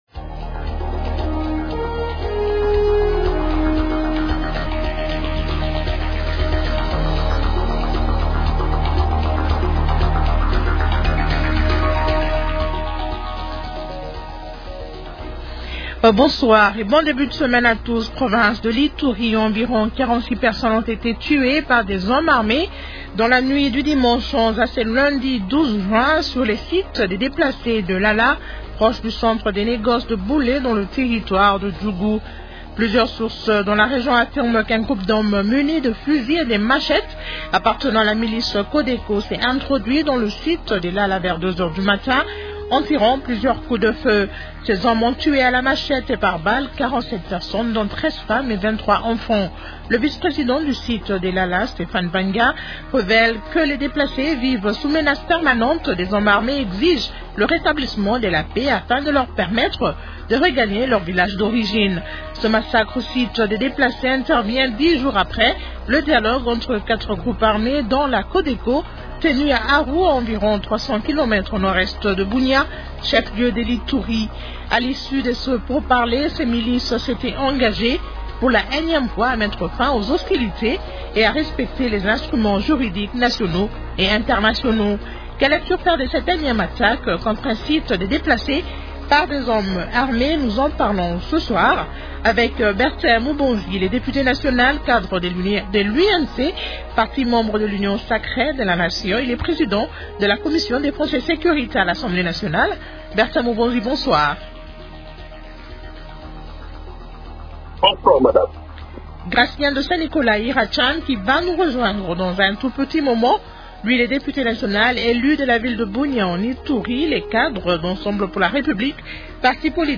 Comment analyser cette énième attaque contre un site des déplacés par des hommes armés ? Invités : -Bertin Mubonzi, député national et cadre de l’UNC, parti membre de l’Union sacrée de la nation.